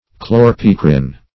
Search Result for " chlorpicrin" : The Collaborative International Dictionary of English v.0.48: Chlorpicrin \Chlor`pi"crin\, n. (Chem.)